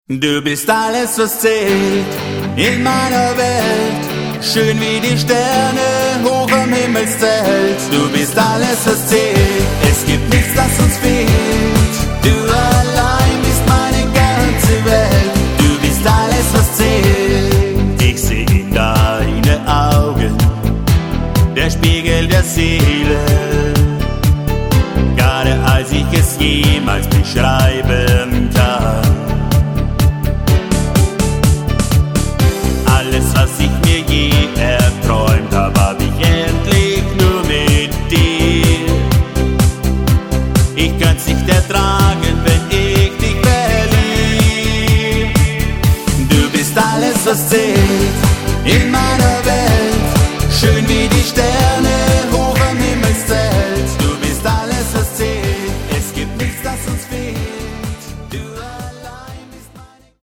Er spielt Keyboard, Gitarre und Gesang.